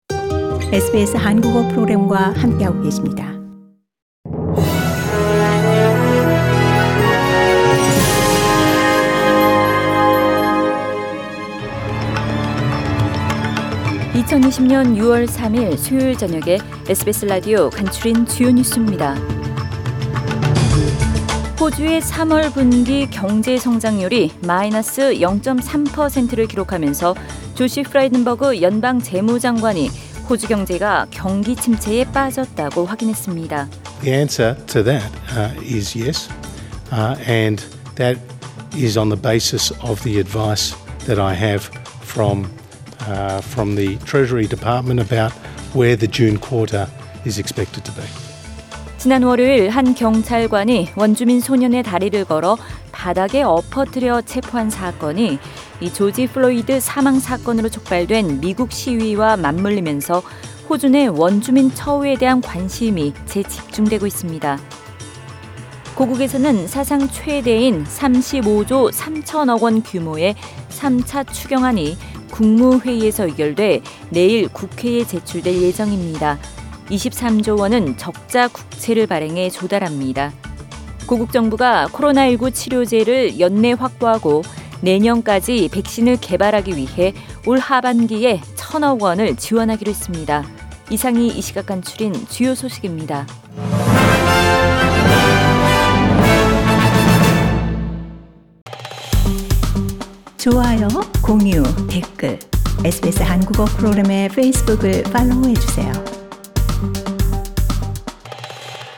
2020년 6월 3일 수요일 저녁의 SBS Radio 한국어 뉴스 간추린 주요 소식을 팟 캐스트를 통해 접하시기 바랍니다.